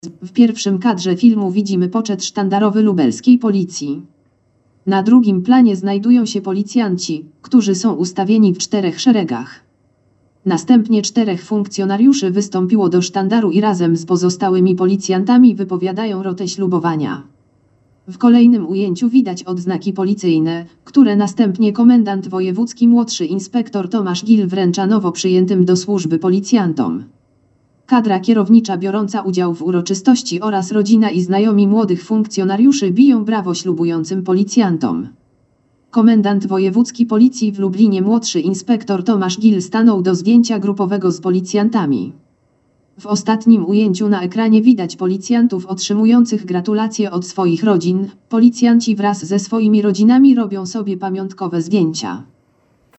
Nagranie audio Audiodeskrypcja 43 nowych policjantów w Lubelskiej Policji